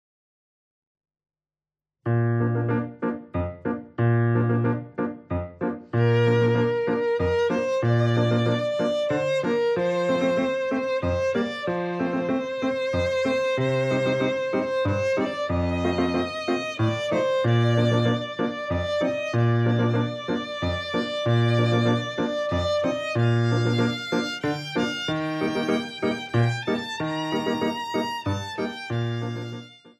Besetzung: Violine